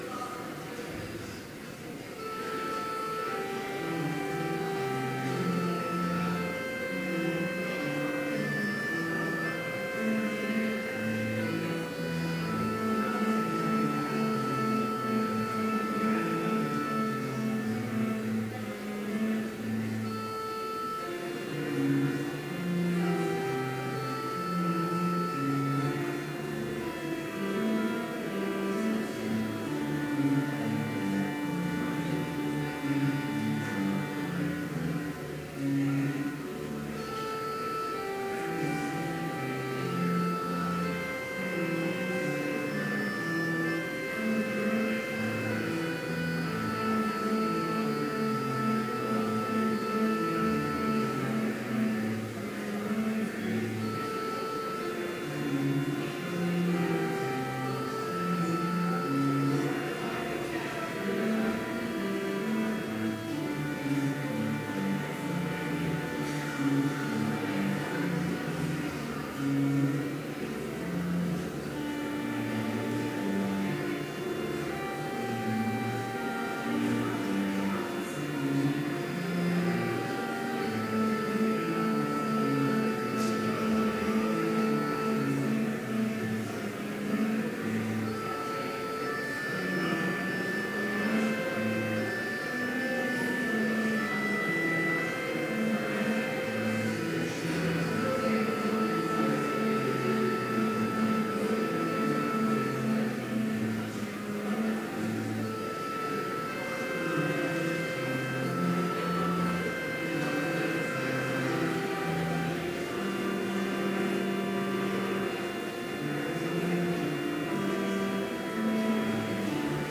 Complete service audio for Chapel - November 7, 2017